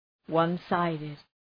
Προφορά
{,wʌn’saıdıd}